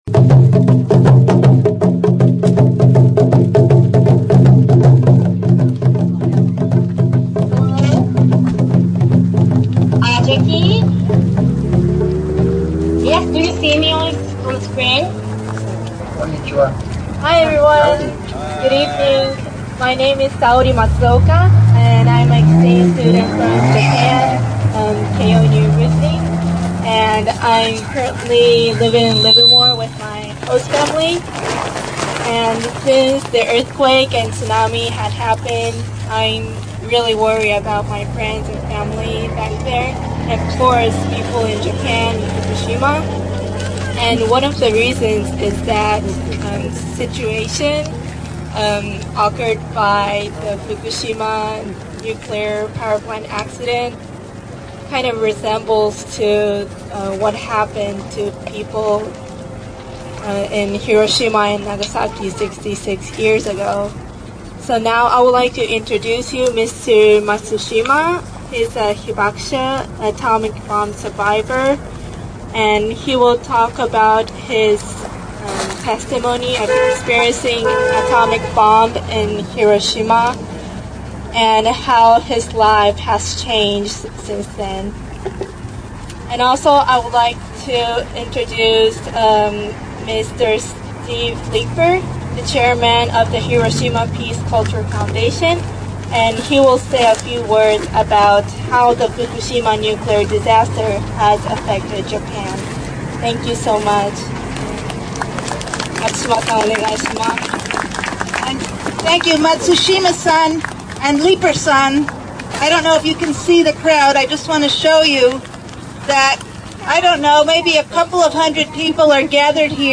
On August 6th, 2011, on the 66th anniversary of the US atomic bombing of Hiroshima, a demonstration organized by Tri-Valley CAREs, Western States Legal Foundation, and a coalition of groups was held just outside of the Livermore lab.
There were speakers and musical performers at Bill Payne Park before a candlelight procession marched to the edge of the Livermore lab, where a live video conference with a survivor of the bomb in Hiroshima was proj